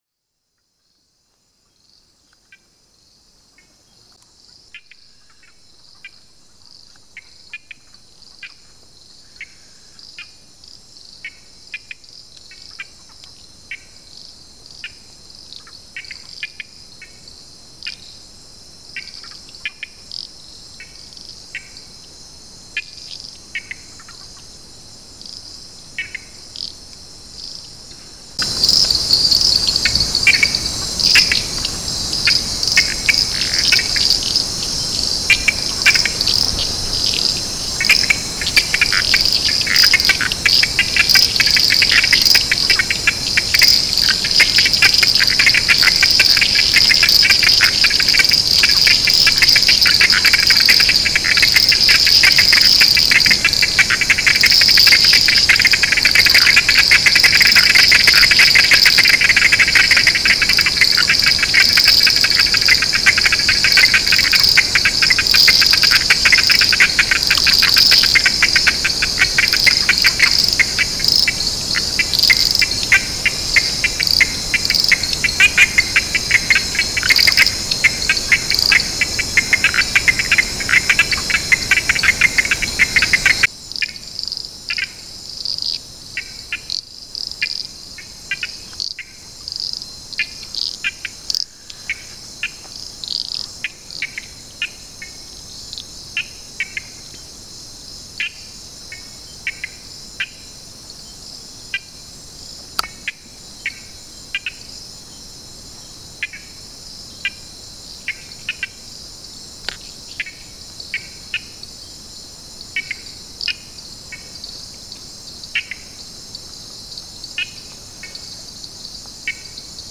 Paying attention to the beauty of the micro and macro sounds of this jungle can be impossible when you are being surrounded by moistly warm air and the "Anopheles Gambiae", a mosquito that is the principal vector of malaria (afflicting more than 500 million people and causes more than 1 million deaths each year).
These field recordings are an aural privilege that not all humans deserve. If you are a nature lover, the layers of heat will be replaced by soothing layers of refreshing eco-sound, and the mosquito bites will become pleasing static sensations.